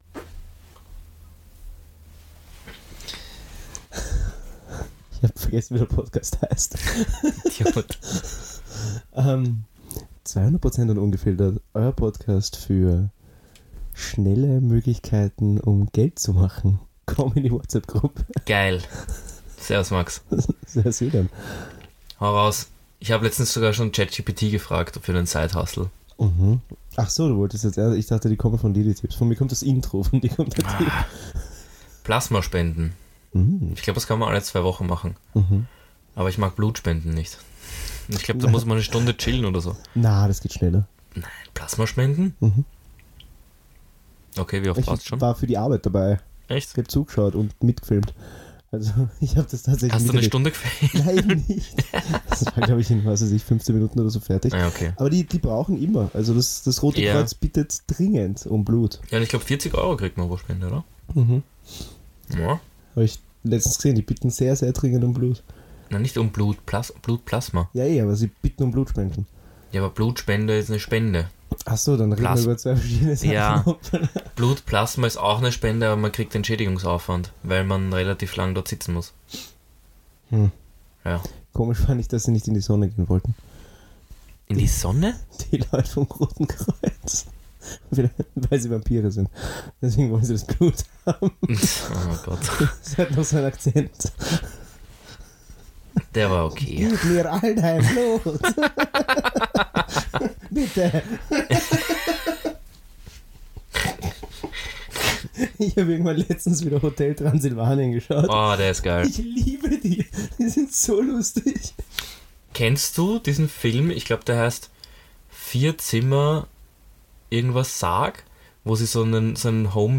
Der Ton ist schlecht wie immer, die Stimmung dafür aber umso gedrückter.